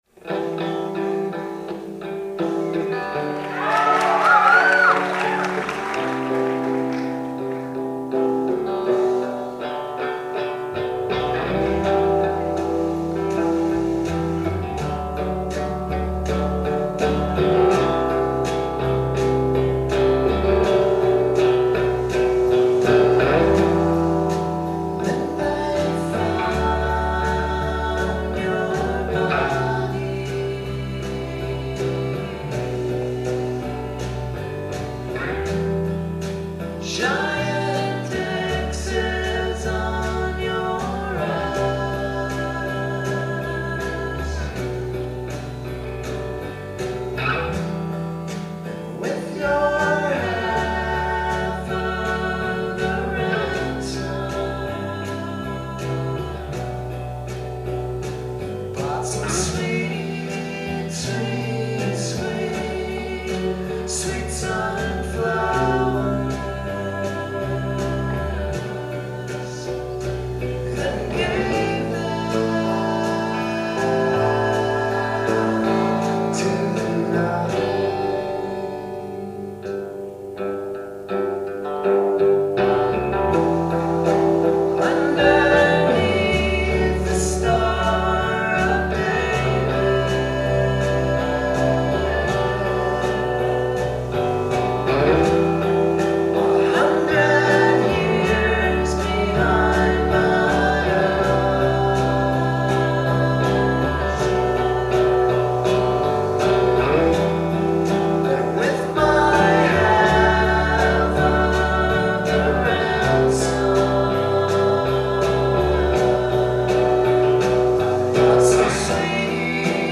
Full sets from the Somerville Theatre